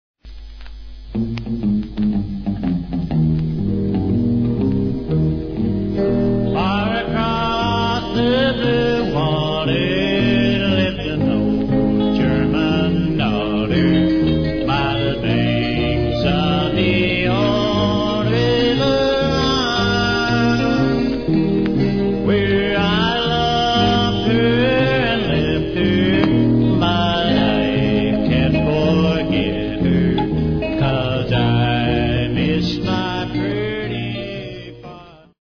US Country singer
Vocals
Traditional Country